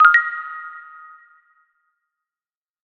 tweet_send.ogg